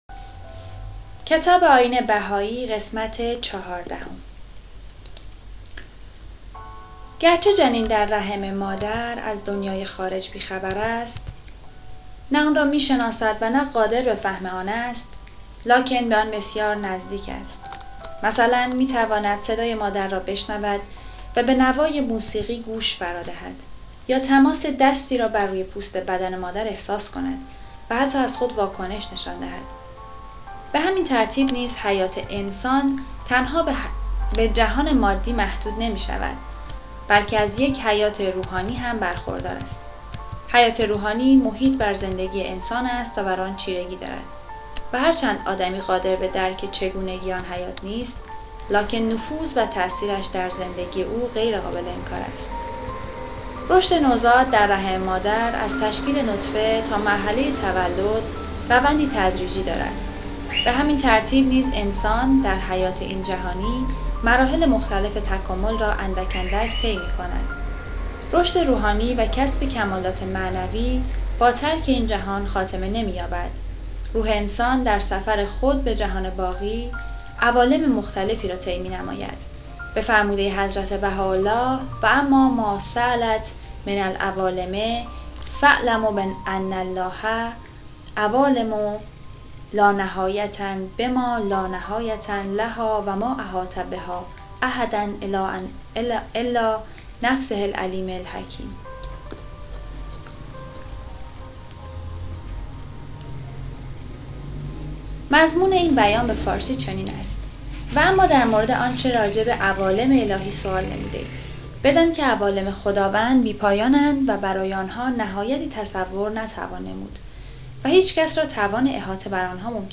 کتاب صوتی «نگرشی کوتاه به تاریخ و تعالیم دیانت بهائی» | تعالیم و عقاید آئین بهائی